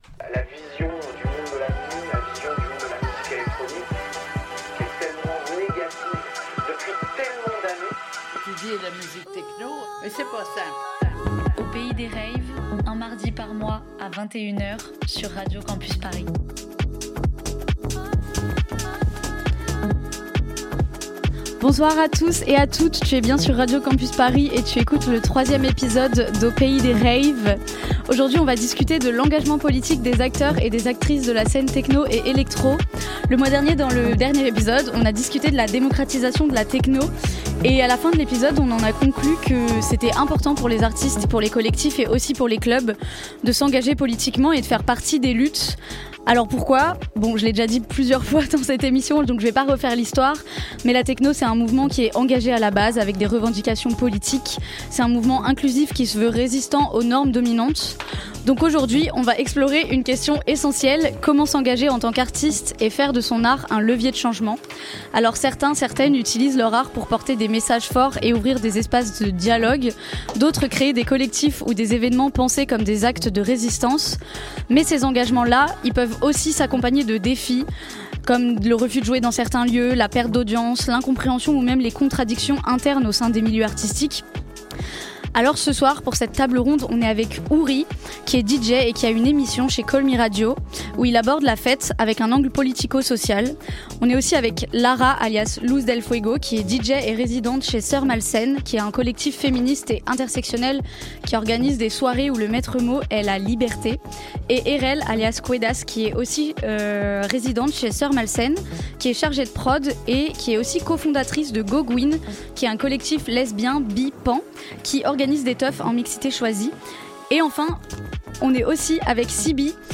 Retrouvez le troisième épisode, en direct, de l'émission Aux Pays Des Raves !